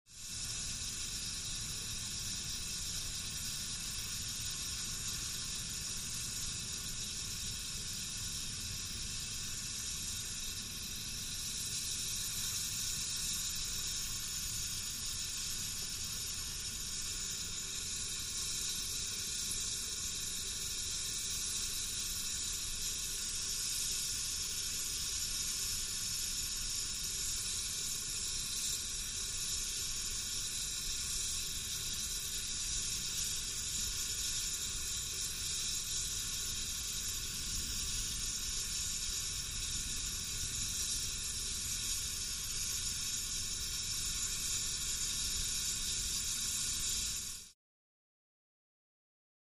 Oil Derrick; Steam Hiss Steady, Distant Perspective